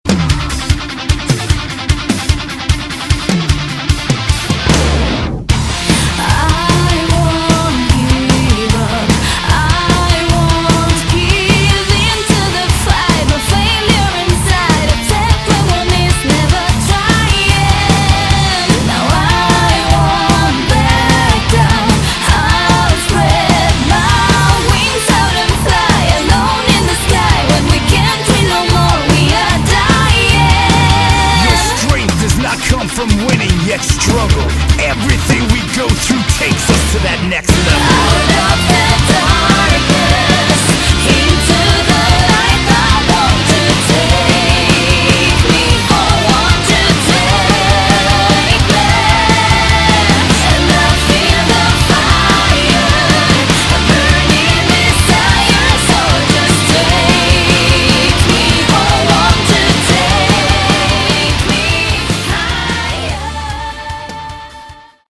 Category: Melodic Metal
guitars, bass, additional vocals
lead vocals
drums